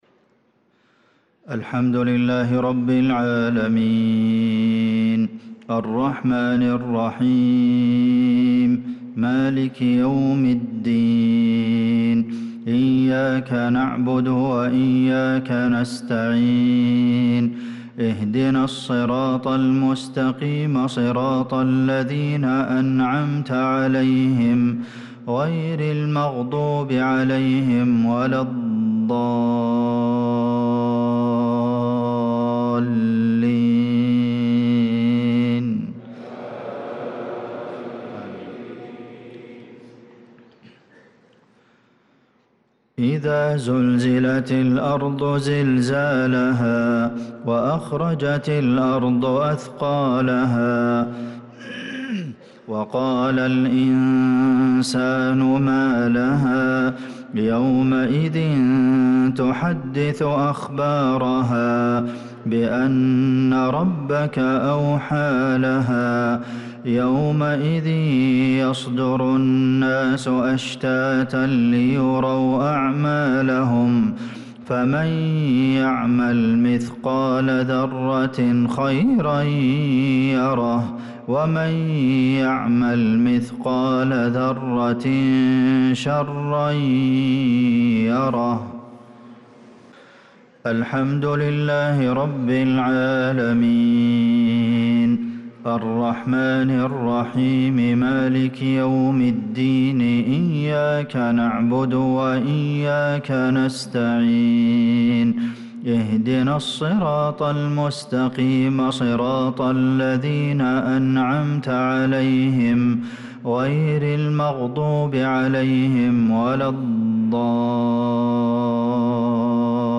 صلاة المغرب للقارئ عبدالمحسن القاسم 7 شوال 1445 هـ